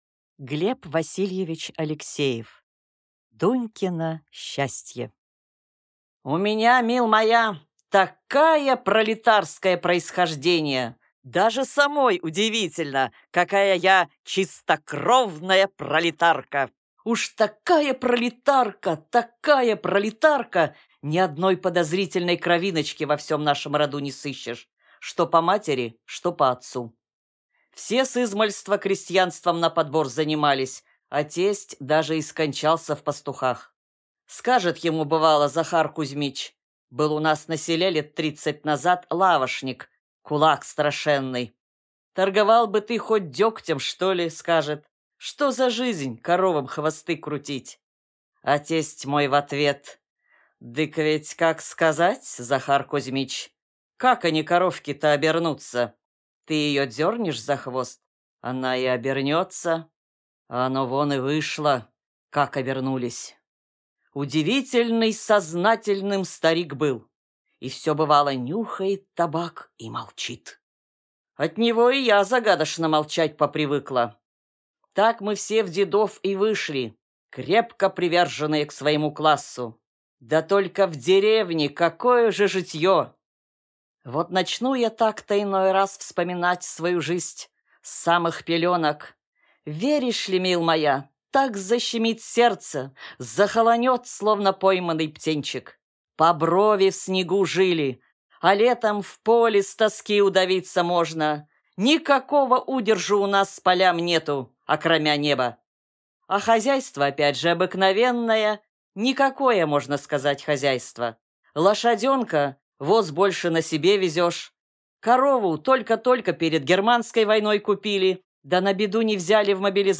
Аудиокнига Дунькино счастье | Библиотека аудиокниг
Прослушать и бесплатно скачать фрагмент аудиокниги